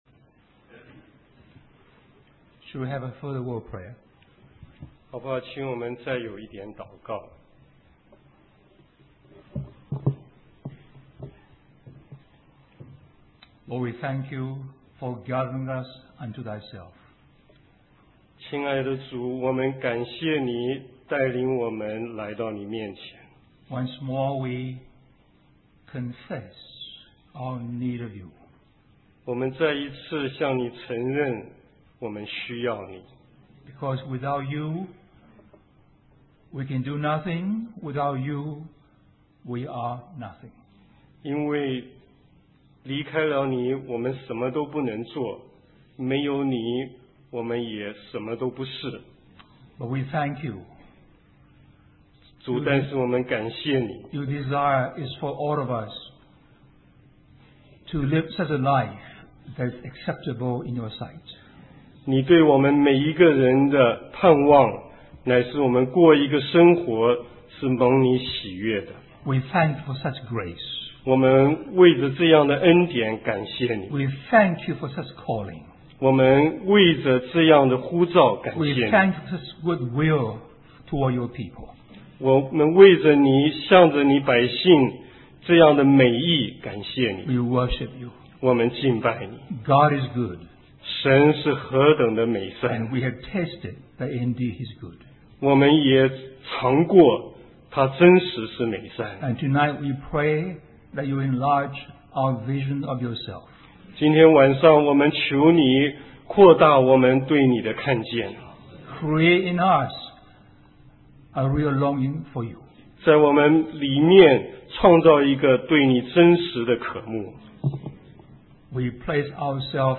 In this sermon, the preacher emphasizes that the offering of evil is not just about having our sins forgiven, but about being accepted by the Holy God. The preacher refers to Genesis 4:3-4, where Cain and Abel both bring offerings to the Lord.